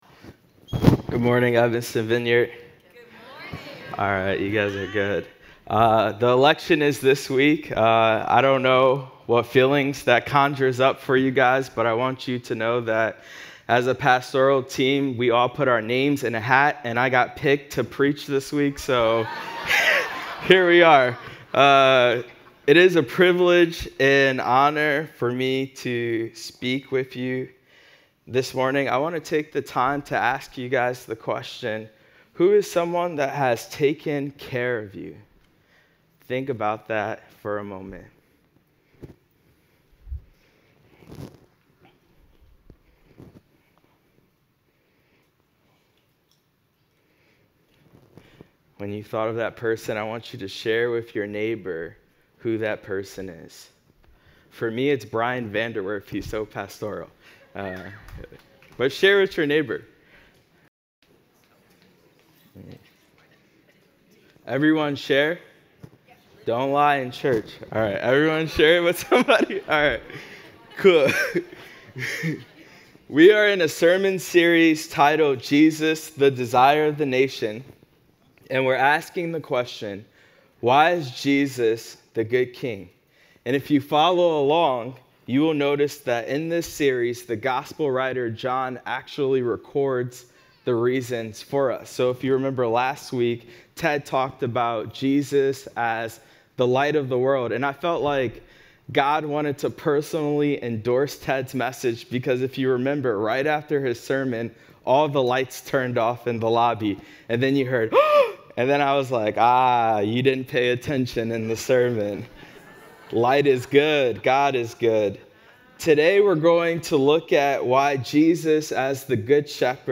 continues our sermon series on Jesus